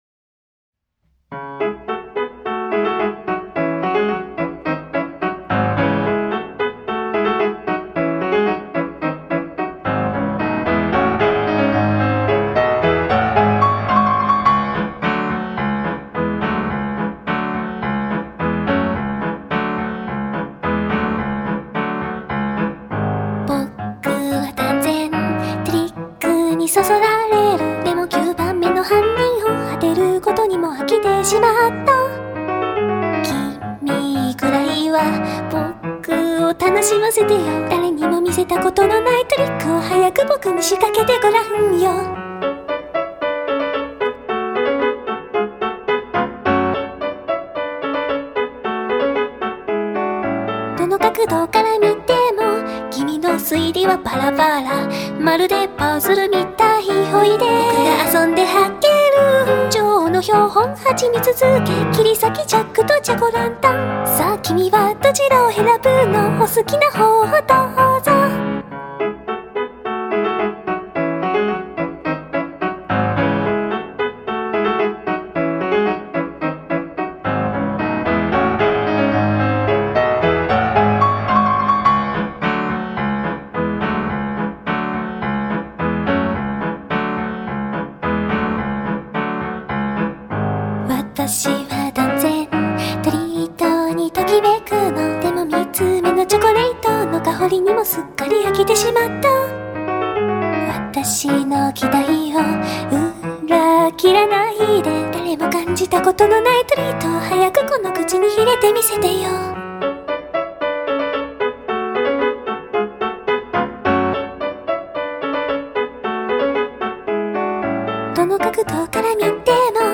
【Vocal (Japanese)】 mp3 DL ♪